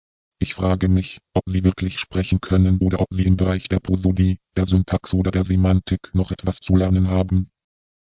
Bsp09 mit konstanter Grundfrequenz.